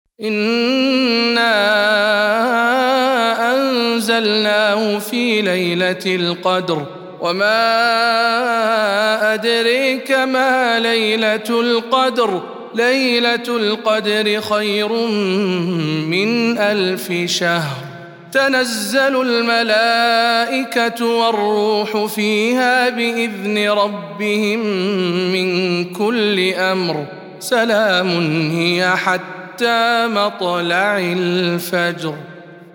سورة القدر - رواية خلاد عن حمزة